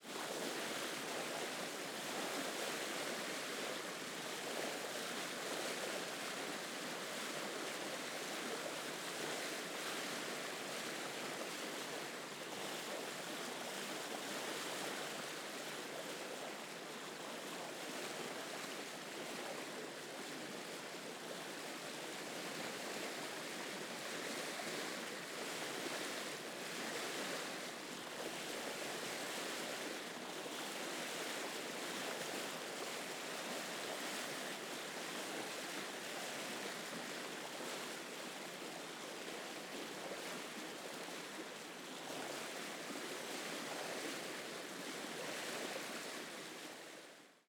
sea.wav